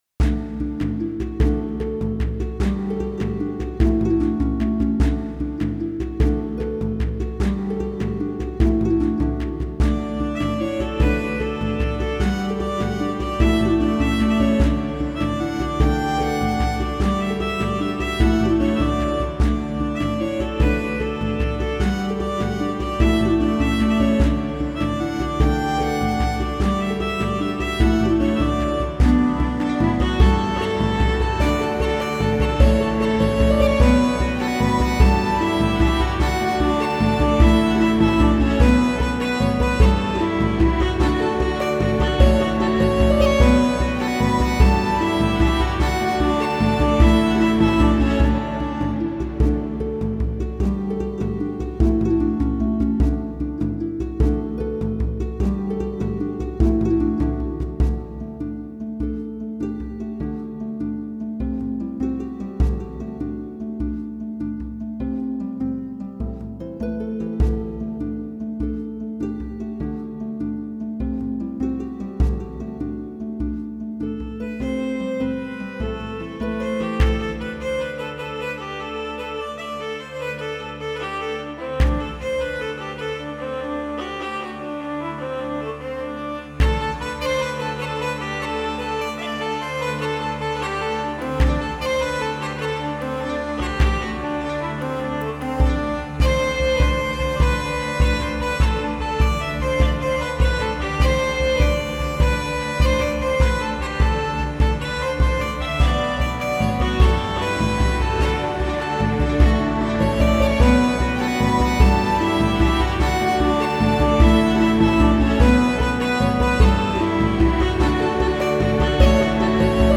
Кельтская